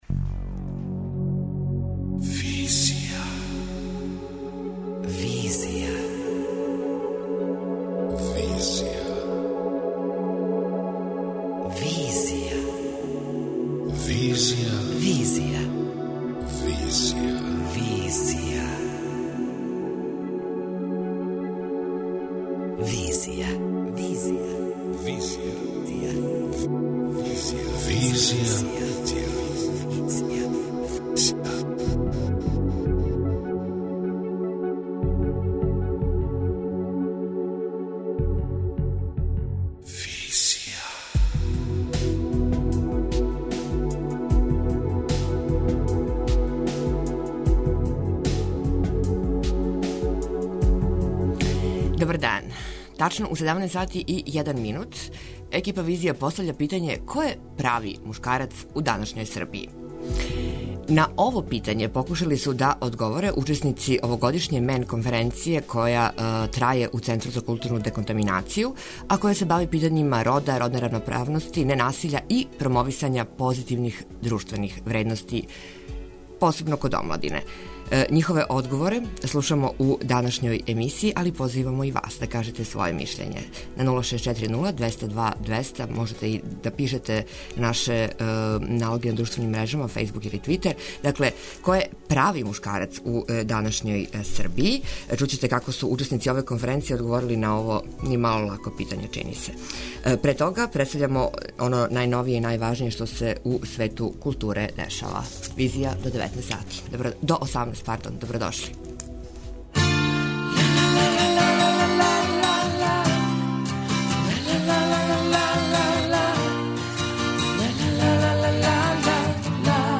На ово питање покушали су да одговоре учесници овогодишње МАН конференције, која се бави питањима рода, родне равноправности, ненасиља и промовисања позитивних друштвених вредности код омладине. Њихове одговоре слушамо у данашњој емисији и позивамо и вас да кажете своје мишљење.